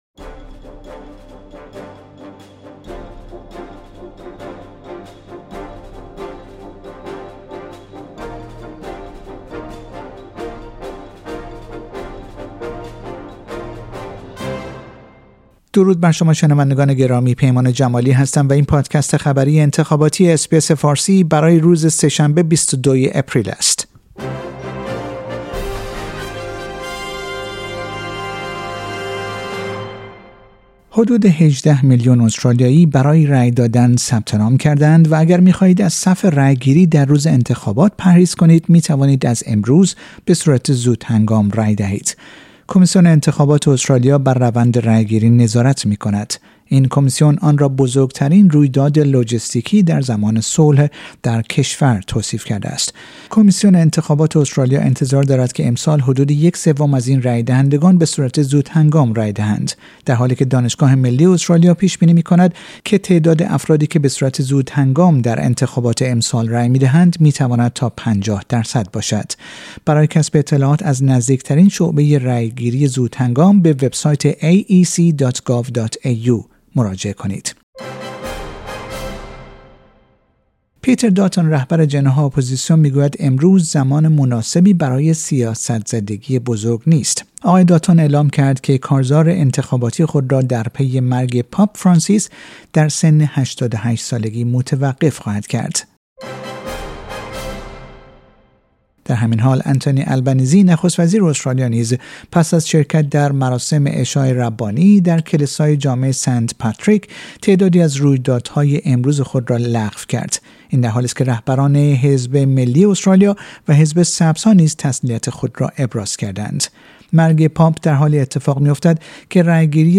این پادکست خبری انتخاباتی اس‌بی‌اس فارسی برای روز سه شنبه ۲۲ آپریل است.